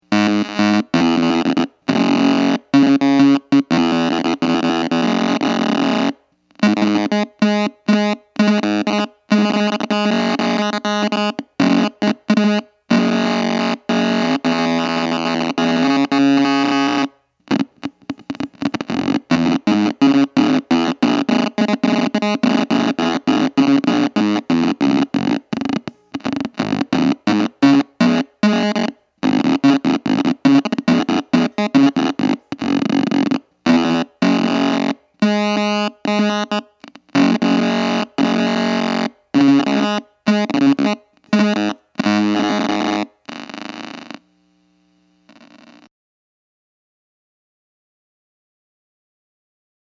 The recording kind of doesn't do justice for the sound. I used a Mini-Marshall guitar amp running on a 9v battery with a pretty tiny speaker for this.
Created some weird sounds plus A440 was silent except for some clicks :). These are all single notes
guitar_proc_test.mp3